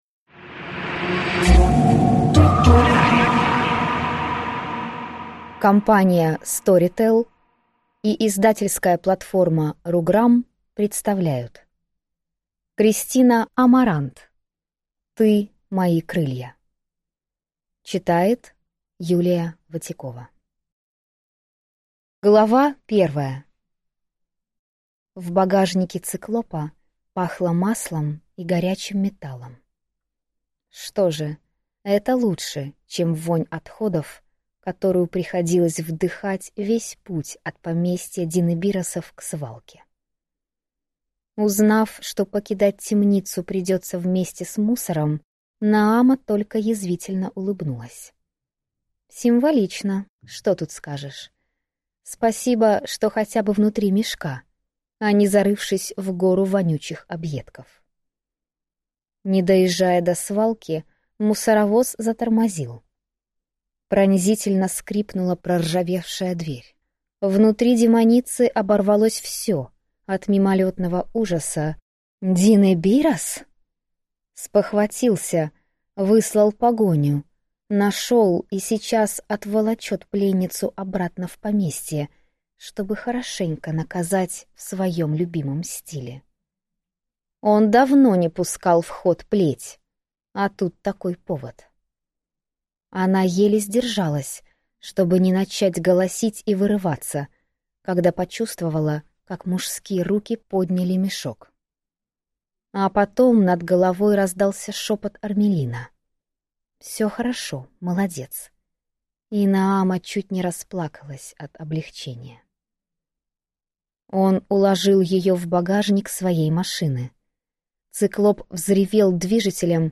Аудиокнига Ты мои крылья | Библиотека аудиокниг